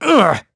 Siegfried-Vox_Damage_01.wav